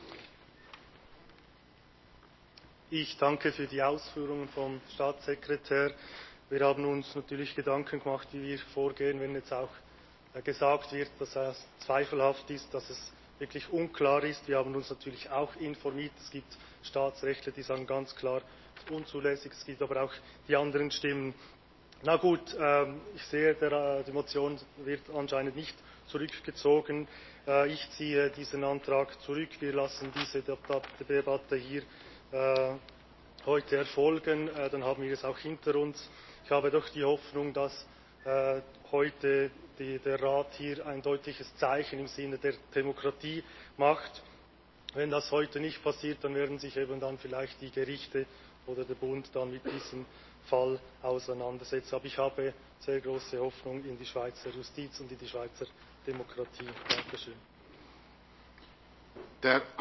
Session des Kantonsrates vom 14. und 15. Februar 2022